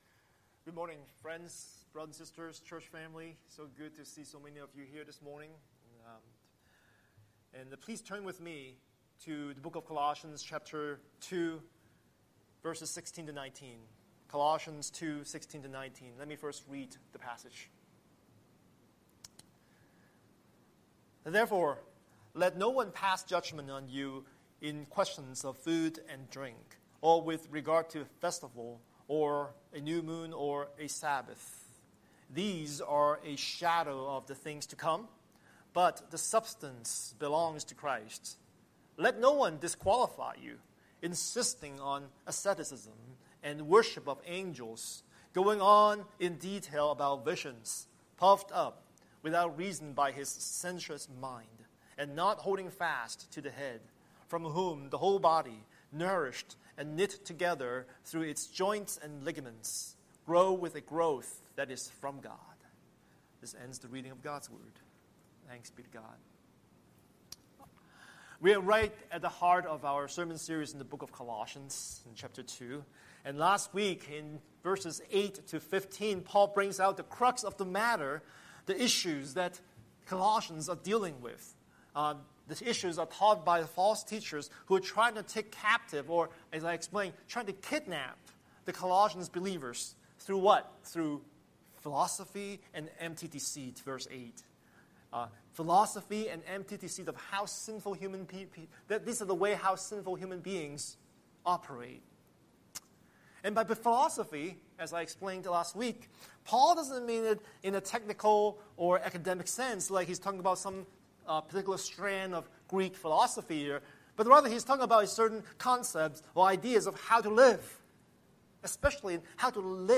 Scripture: Colossians 2:16-19 Series: Sunday Sermon